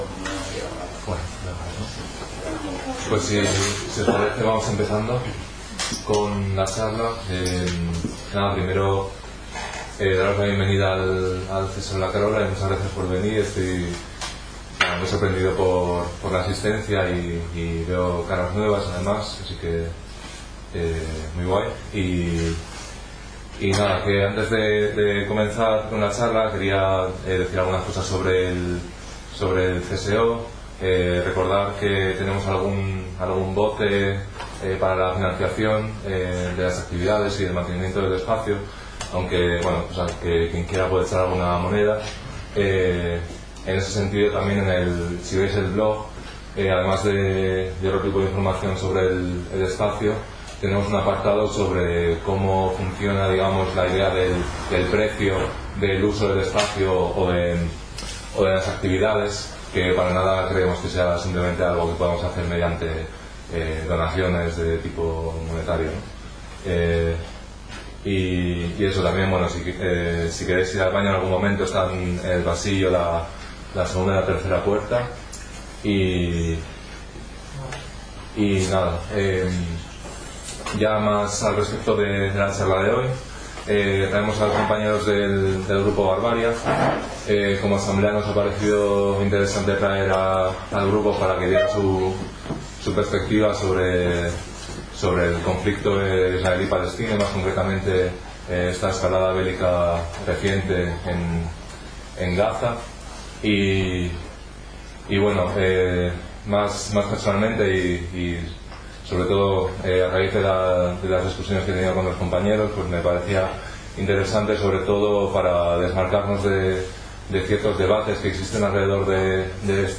El próximo 9 de marzo a las 18:00 el Grupo Barbaria presentará en el CSO La Karola su postura respecto al escalamiento del conflicto en Palestina. Tras la charla habrá un espacio de debate en el que se discutirá entre todas distintos aspectos de la charla que vayan saliendo, además de dudas y diferentes perspectivas.
Os dejamos por aquí también la grabación de la charla para que la escuchéis las que no pudisteis venir:
Charla-palestina-Barbaria.mp3